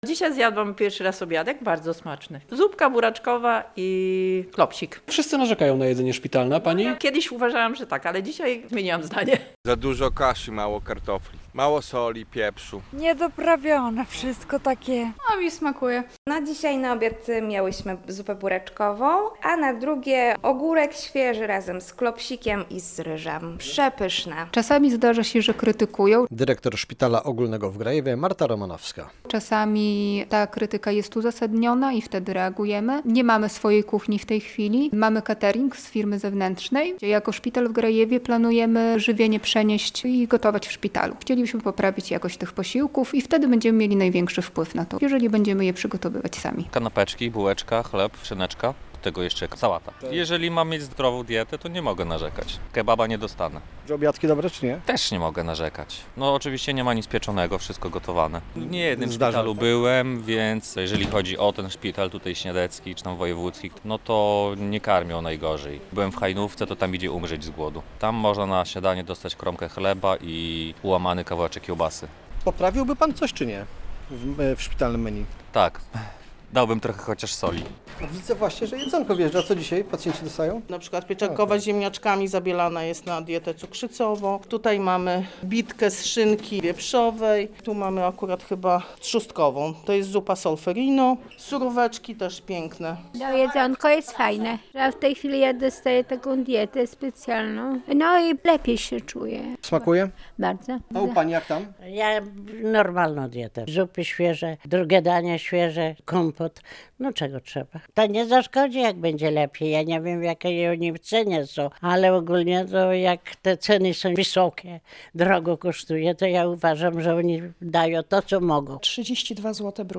My postanowiliśmy odwiedzić cztery podlaskie szpitale, by dowiedzieć się co o szpitalnym jedzeniu mówią pacjenci.
Pacjenci, z którymi rozmawialiśmy, generalnie chwalą sobie szpitalne posiłki, choć przyznają, że są one słabo doprawione.